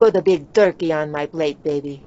nat_putaturkeyonplate.wav